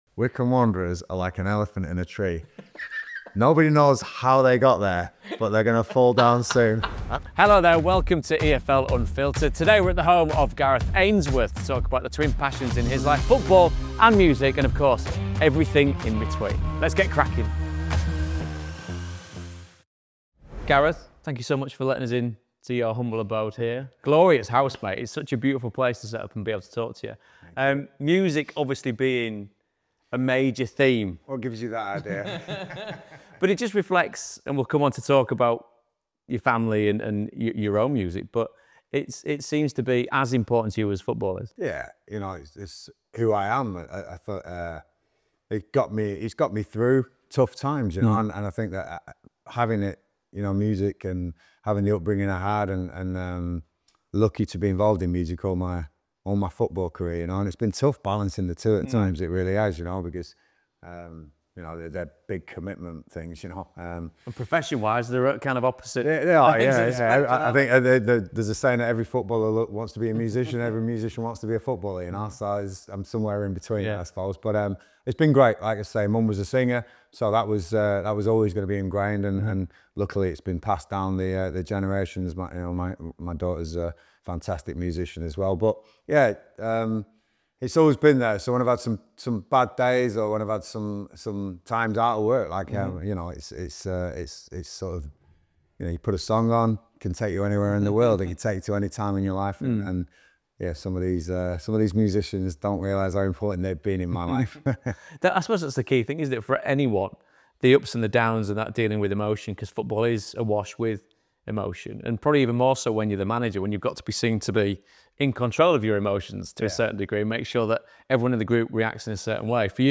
He's a total one off and a brilliant host, as David Prutton found out when he was kindly invited over to Gareth's gaff for the day, back in October of last year.
This is a fascinating insight into the tightrope that everyone in football management needs to tread, and once the relaxed chat around the bar and the trophy room has concluded, Gareth has one final surprise for Prutts!